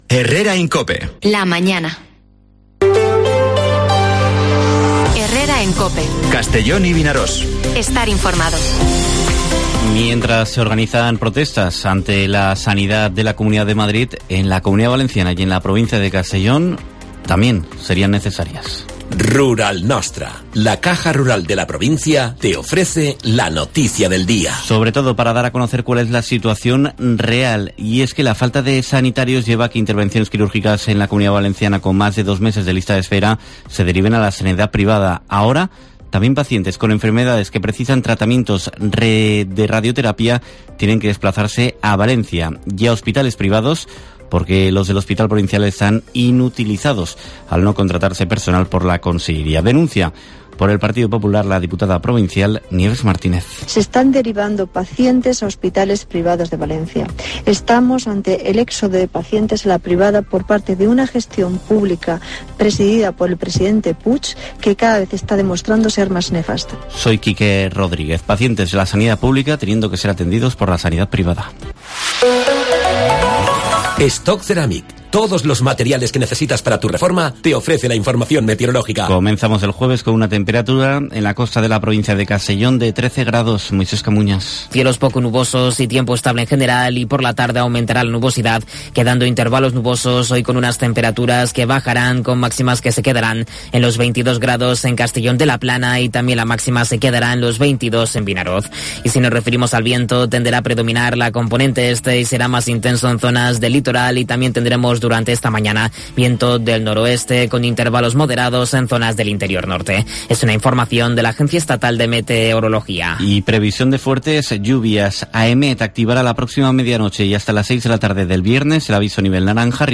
Informativo Herrera en COPE en la provincia de Castellón (10/11/2022)